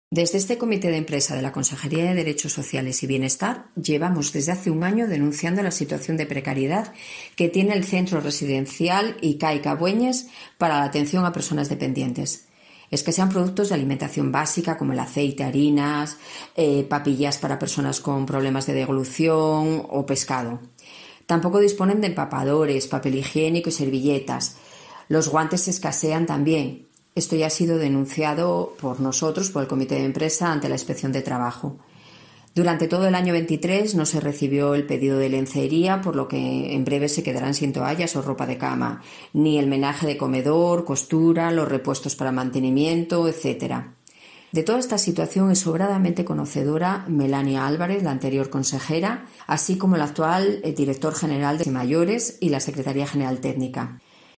La presidenta del Comité de Empresa denuncia en COPE la situación que vive el centro